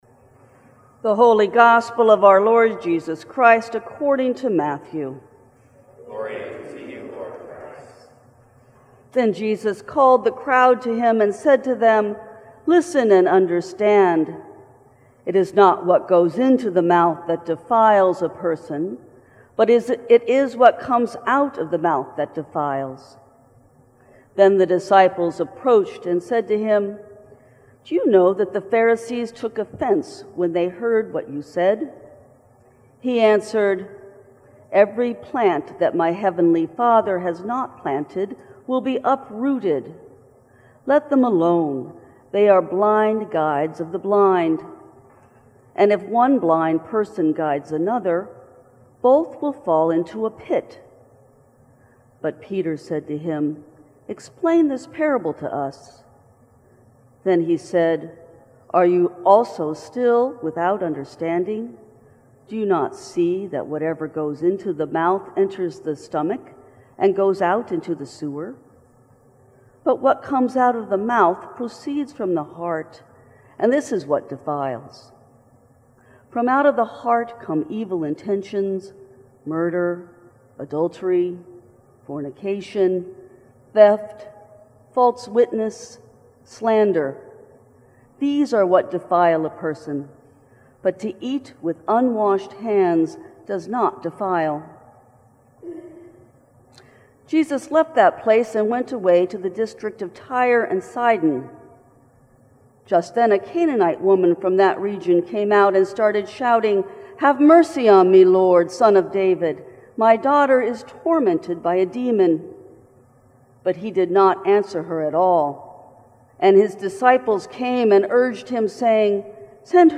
Sermon from 8 AM service.